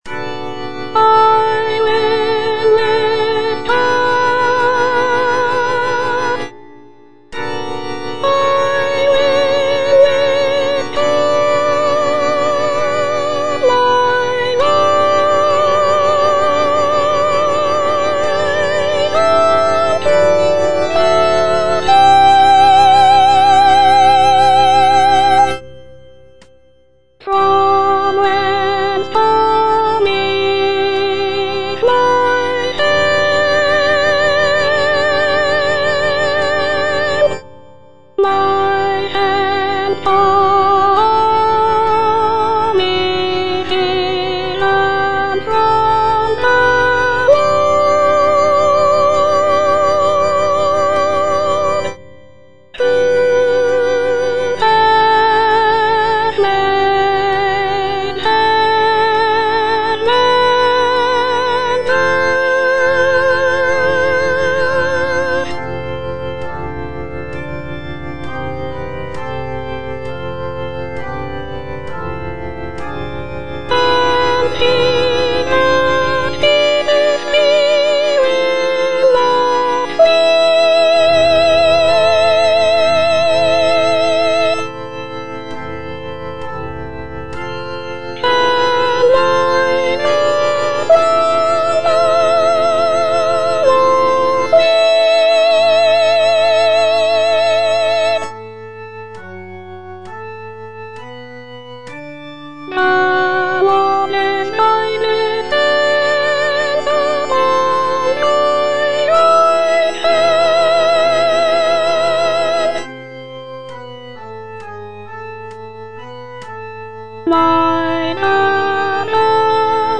Soprano I (Voice with metronome)
is a choral work